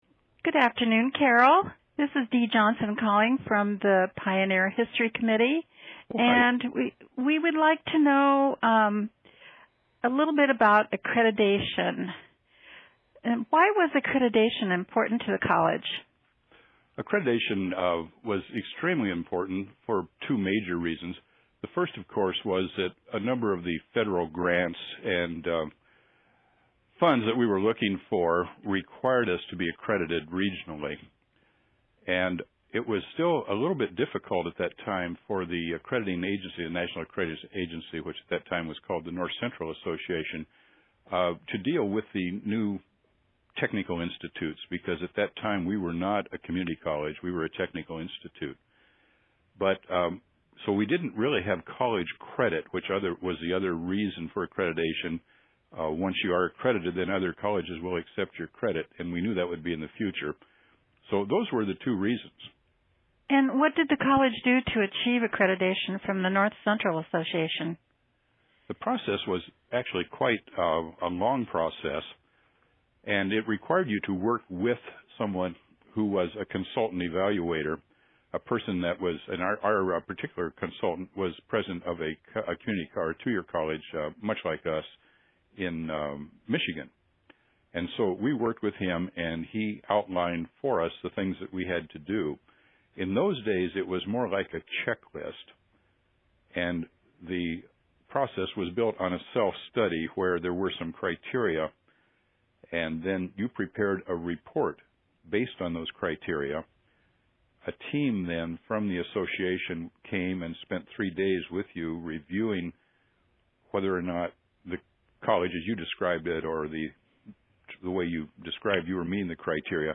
Interviews
Listen to conversations with former DMACC employees by clicking an audio player below.